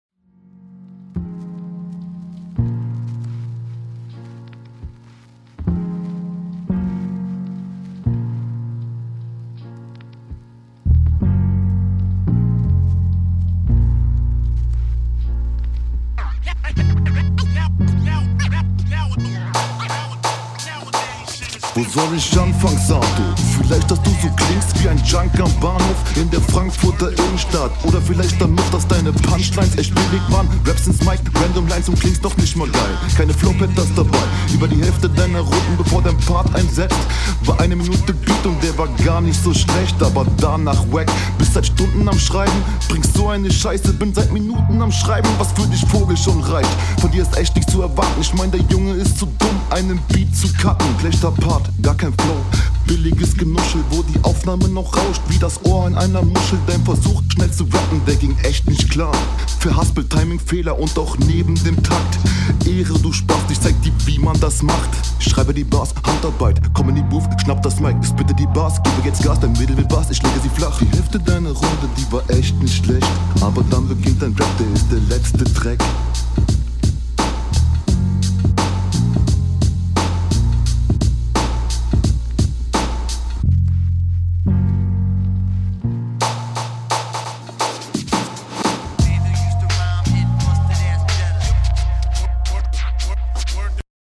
Dein Stimmeinsatz klingt sicherer als zuvor.